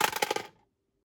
magdrop.ogg